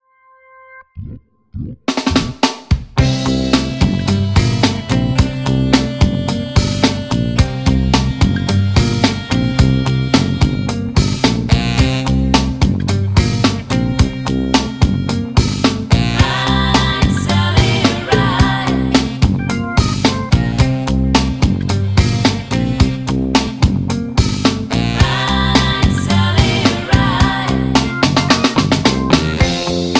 Backing track Karaoke
Pop, Oldies, 1960s